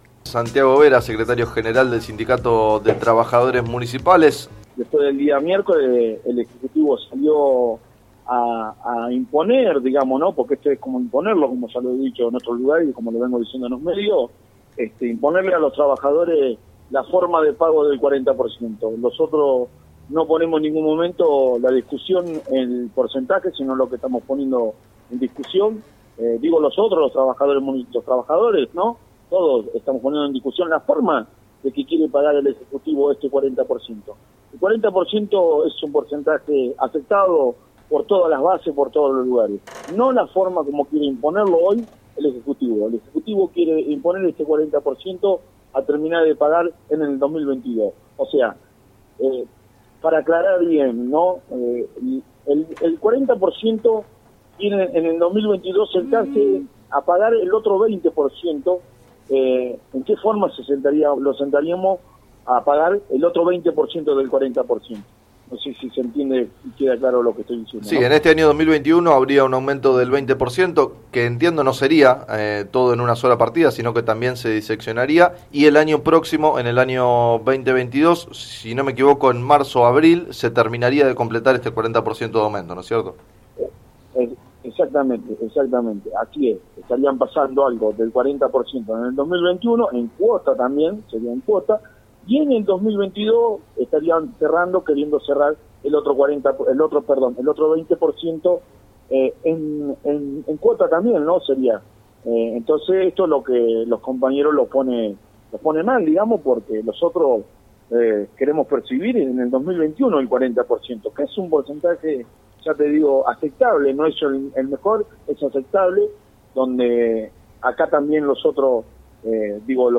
en declaraciones al programa “Sobre las cartas la mesa” de FM Líder 97.7 anticipó que si no hay acuerdo habrá paro por tiempo indeterminado.